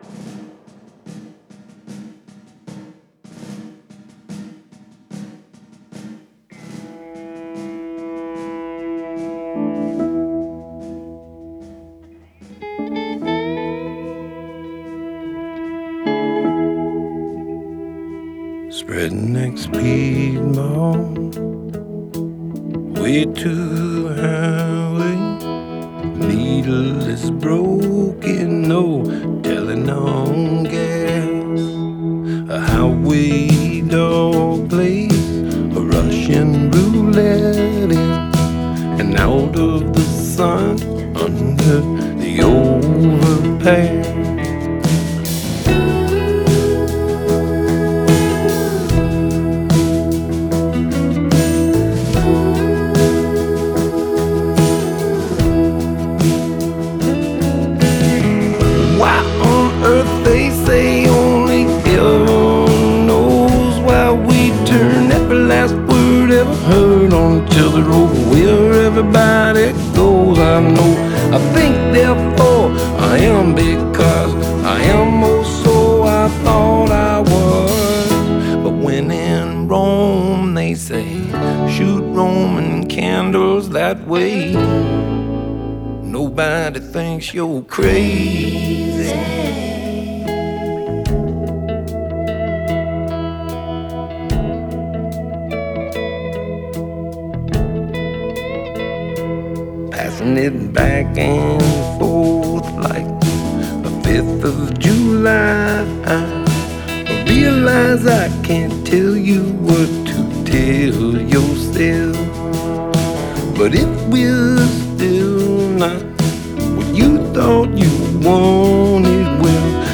Genre: Folk Rock, Blues, Americana